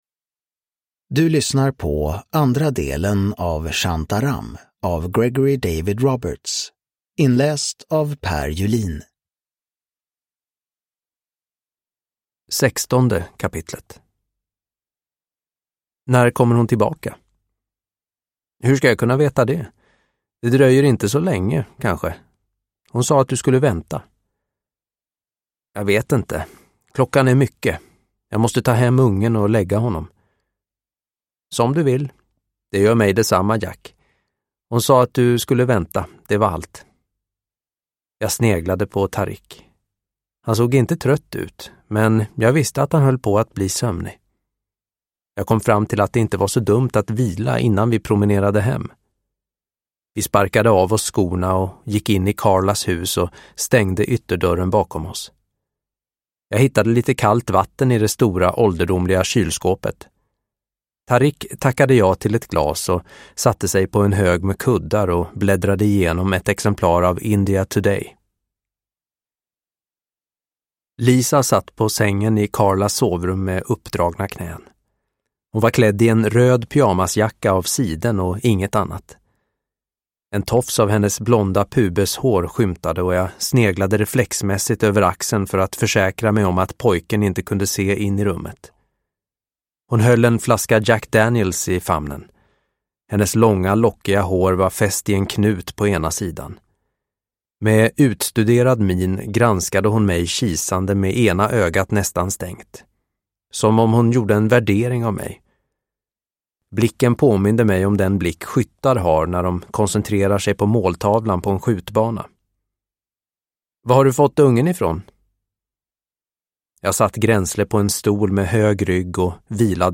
Shantaram. Del 2 – Ljudbok – Laddas ner